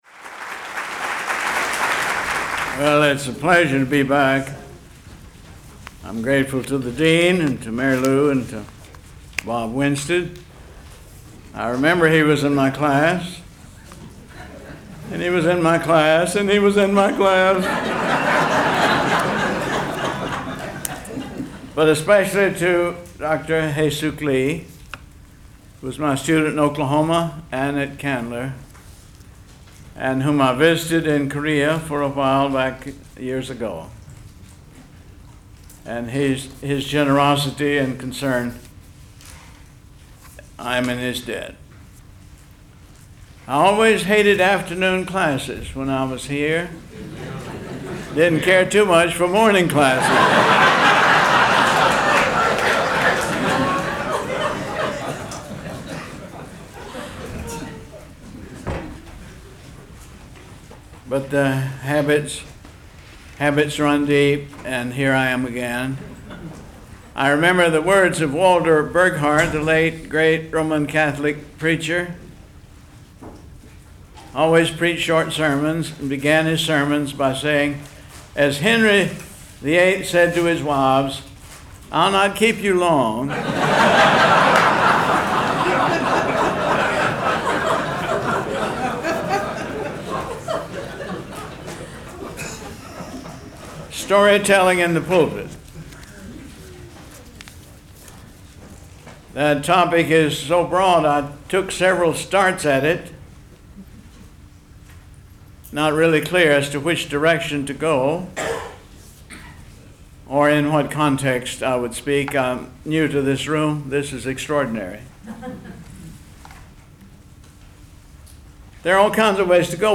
Candler School of Theology, Room 252